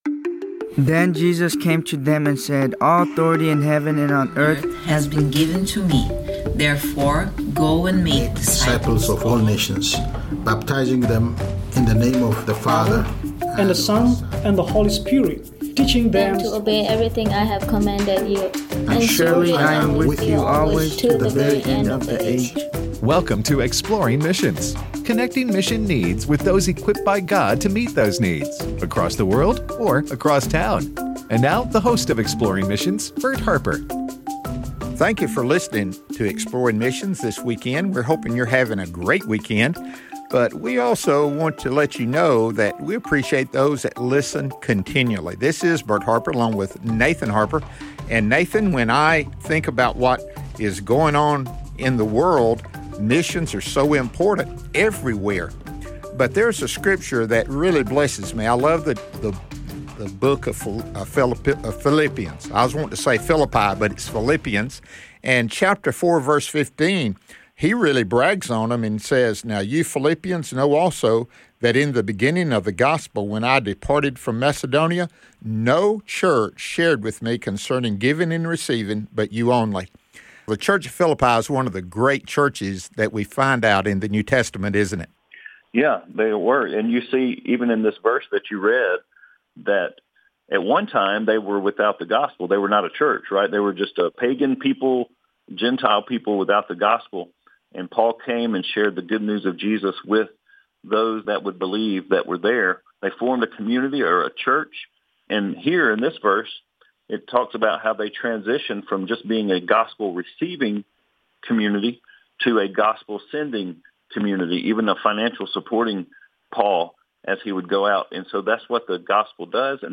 Ministering In Brazil: A Conversation